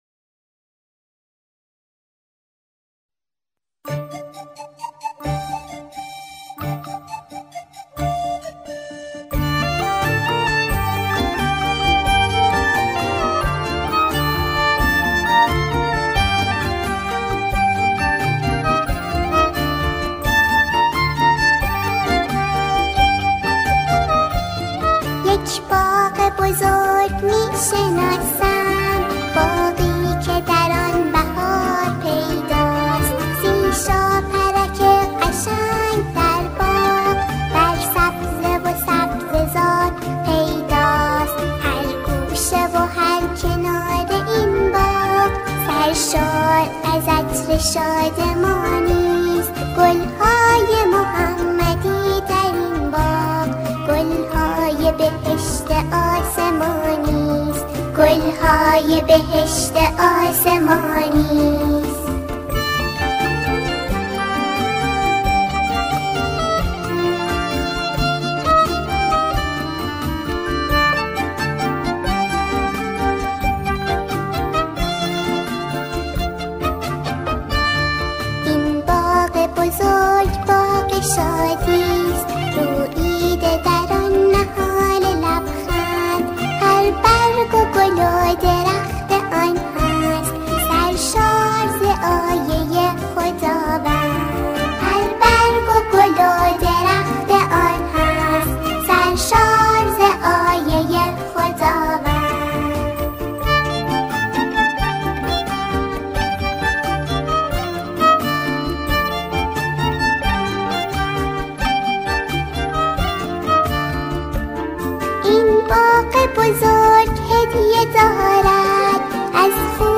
قطعه کودکانه